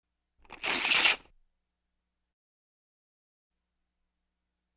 Бумага звуки скачать, слушать онлайн ✔в хорошем качестве